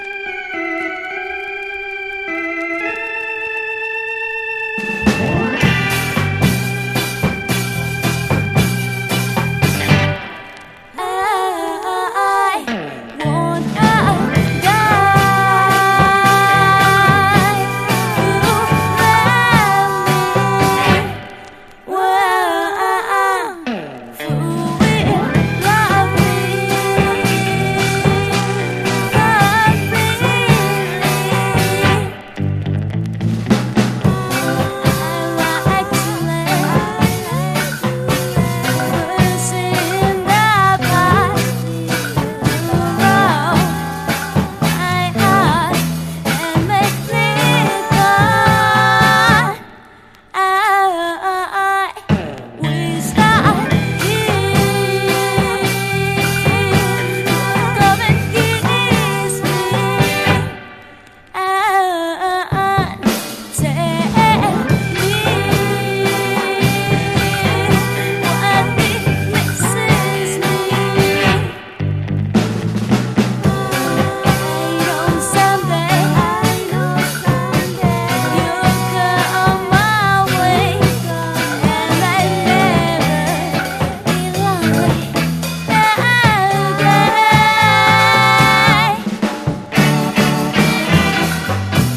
奇跡のジャパニーズ・ガールズ・ロックステディ！